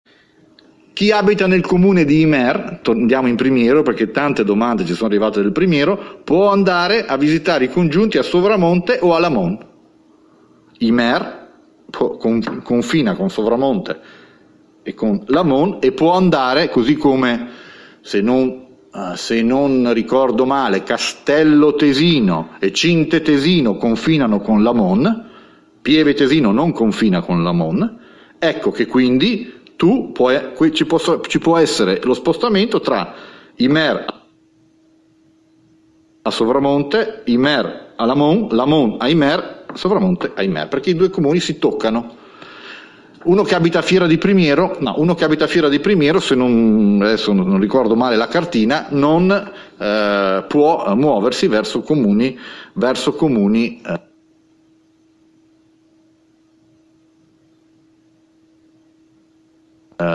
“Sono ammessi – ha ricordato ancora Fugatti – solo spostamenti tra comuni vicini, confinanti e non altri comuni più distanti e solo per le motivazioni previste”, finchè non arriveranno nuove disposizioni dal Governo.